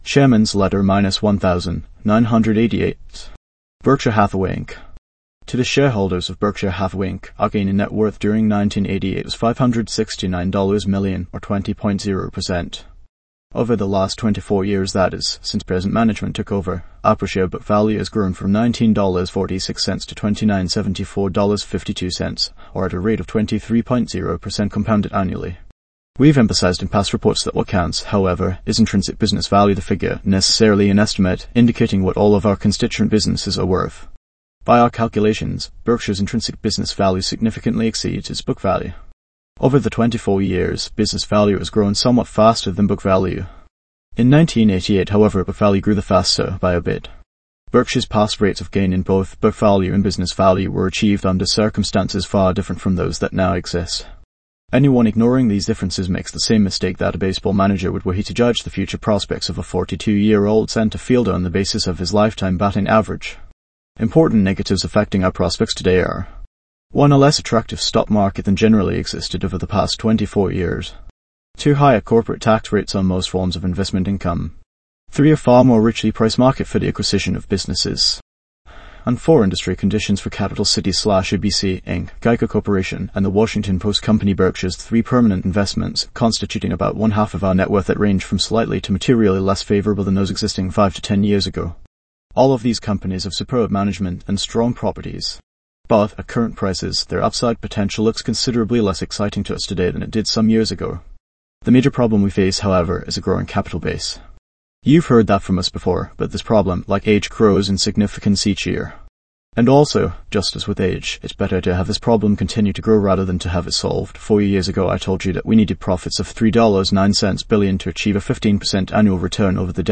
value-investors-tts